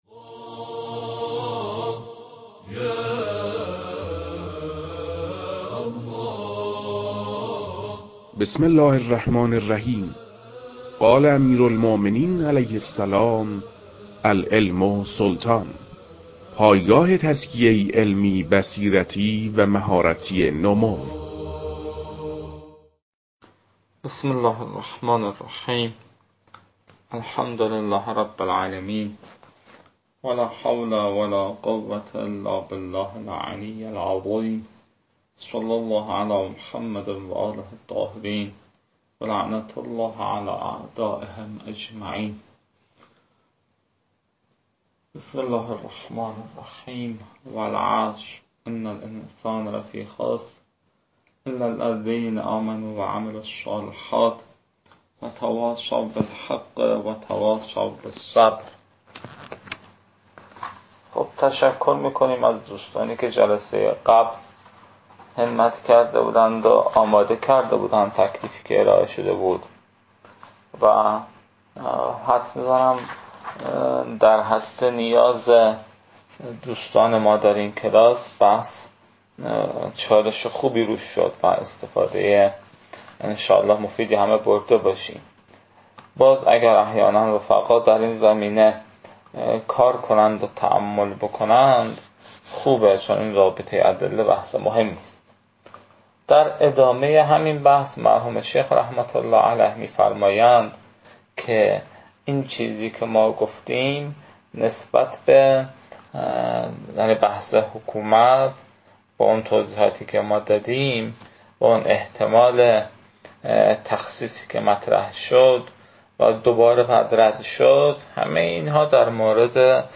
این فایل ها مربوط به تدریس مبحث برائت از كتاب فرائد الاصول (رسائل) متعلق به شیخ اعظم انصاری رحمه الله می باشد